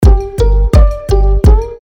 • Качество: 320, Stereo
без слов
интригующие
загадочные
Звук с интригой на уведомления